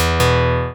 ScreenReaderOn.wav